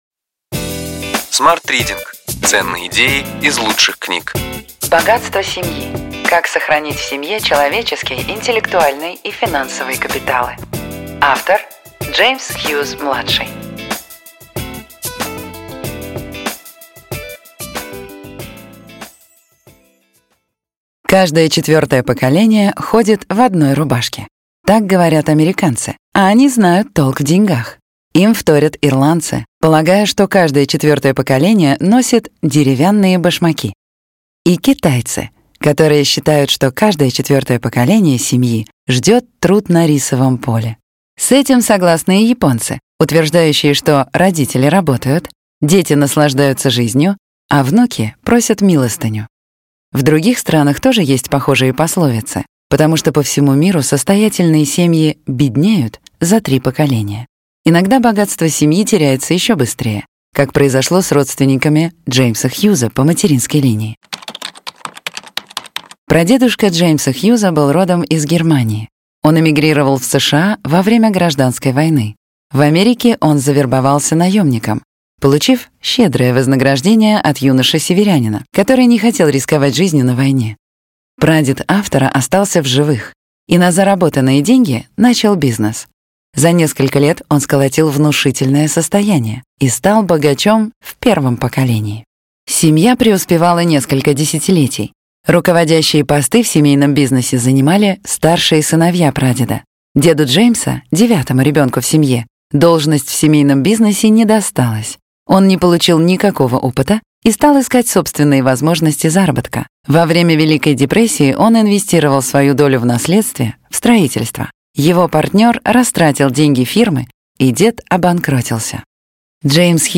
Аудиокнига Ключевые идеи книги: Богатство семьи. Как сохранить в семье человеческий, интеллектуальный и финансовые капиталы.